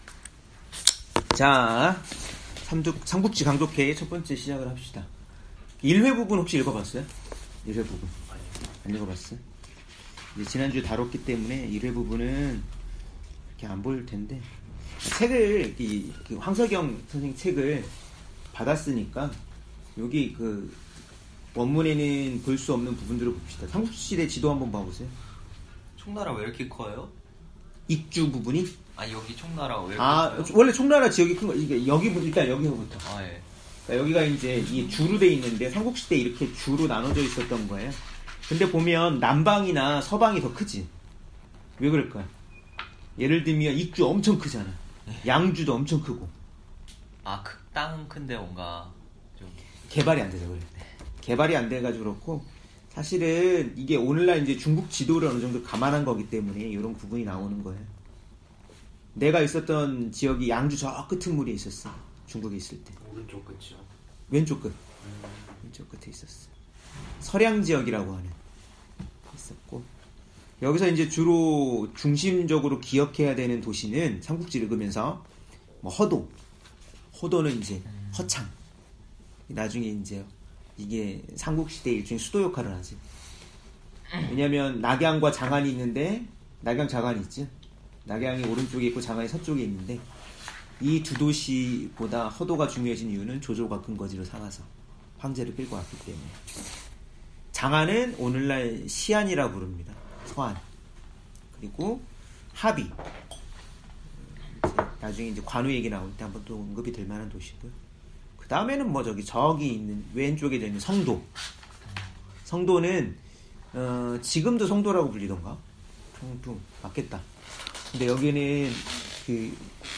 삼국지 강독회 #1
삼국지 강독회를 진행하고 있습니다.